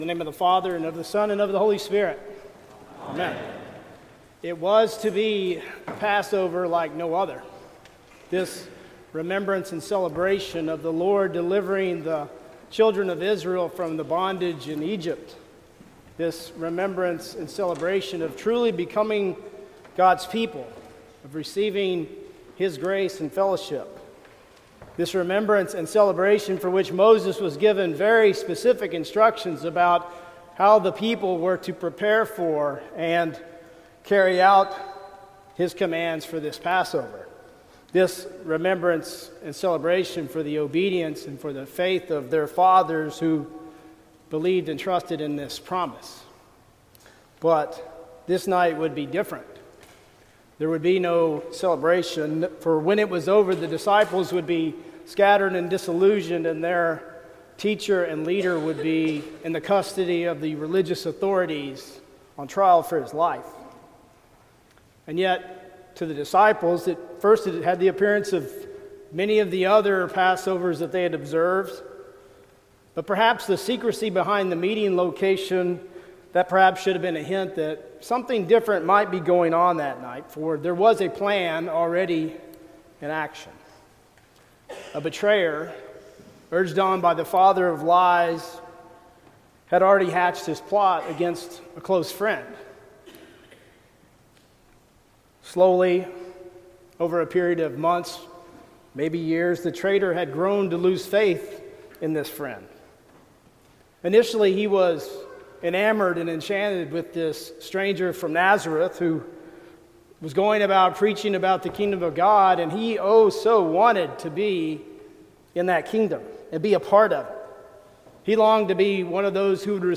Sermon for Lent Midweek 1